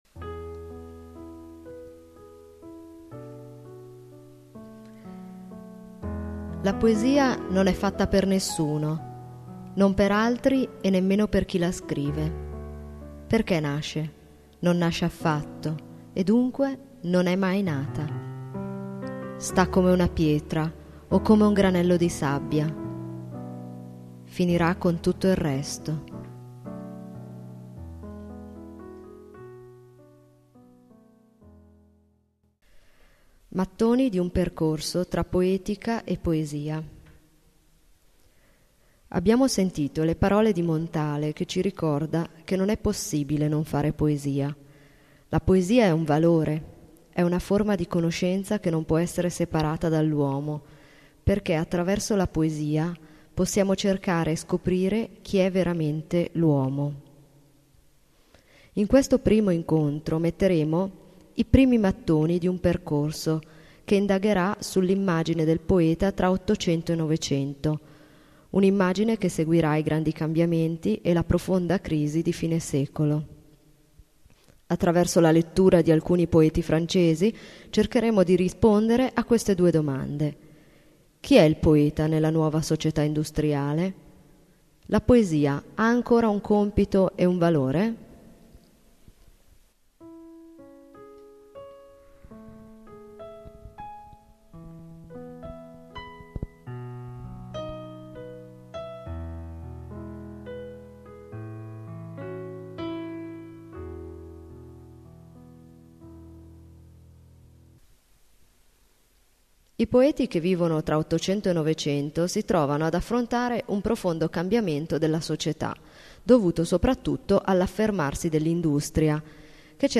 "Il poeta e il mondo" premiato da Garamond é una serie radiofonica composta da lezioni audio che i ragazzi possono ascoltare, scaricare da internet (anche in automatico), mettere sui loro i-pod, risentirle quando, dove e come vogliono.
assaggio podcast poesia.mp3